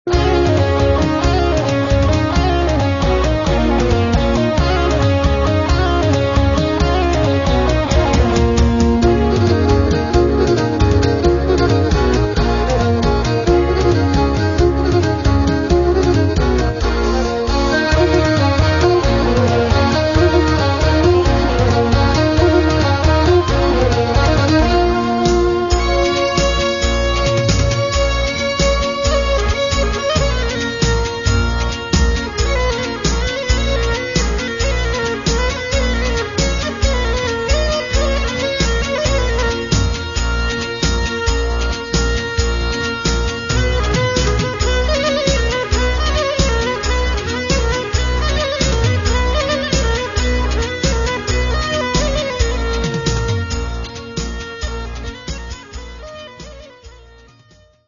The most common Pontic dance. Similar music & rhythm also found in Iran.